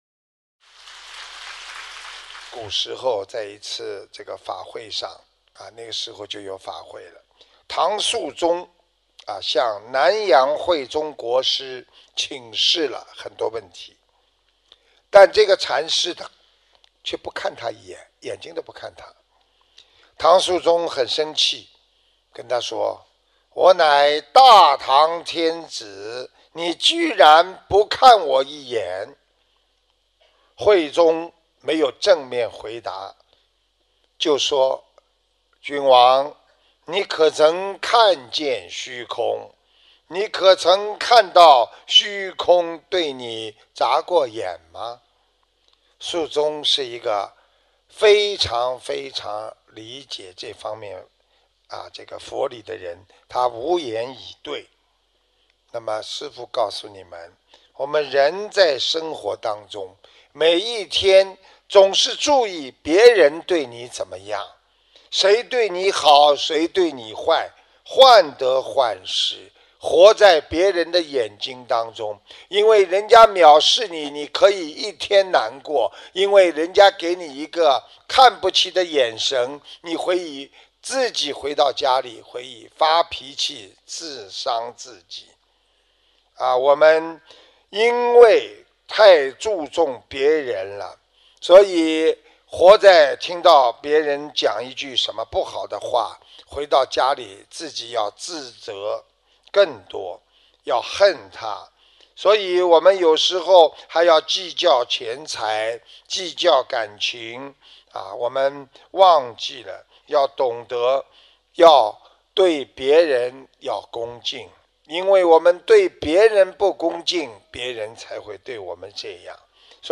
布里斯本法会开示！